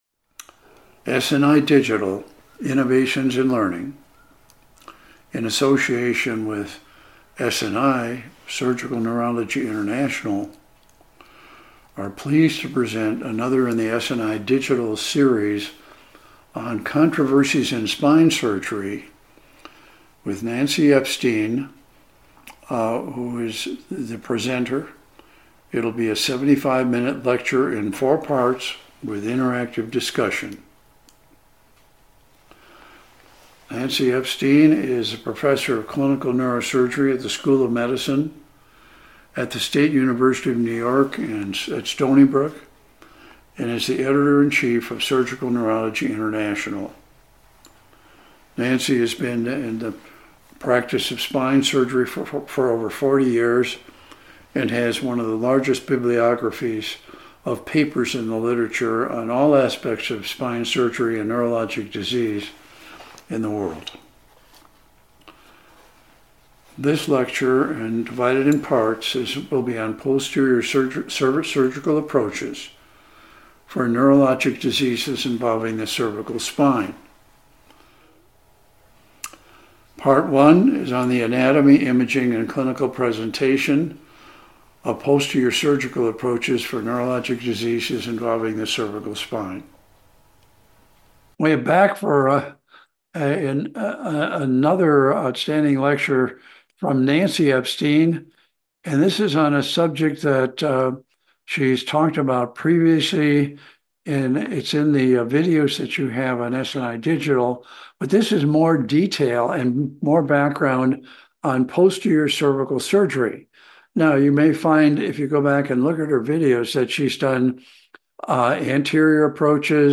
Four Part Lecture and Discussion 80 minutes total.